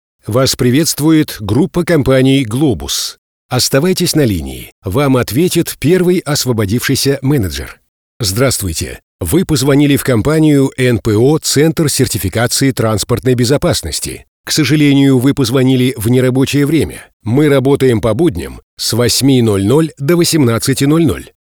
Автоответчик